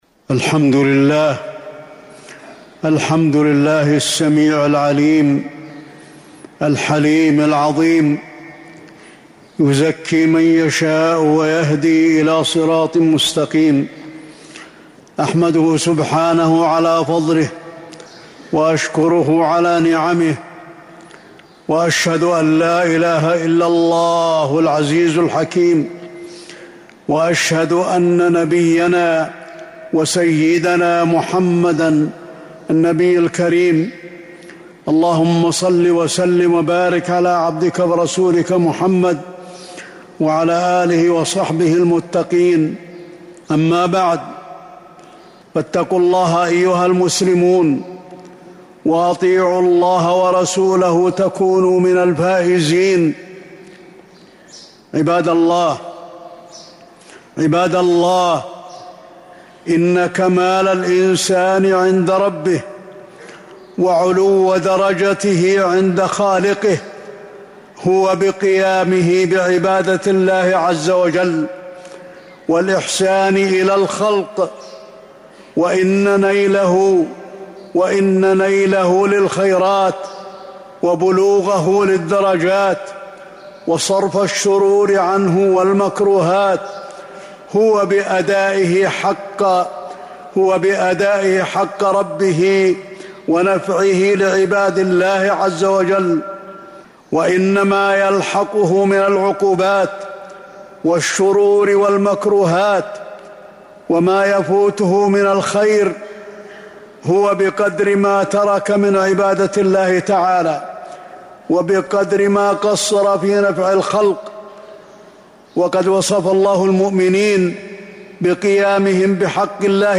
المدينة: رمضان وصفات المؤمنين - علي بن عبد الرحمن الحذيفي (صوت - جودة عالية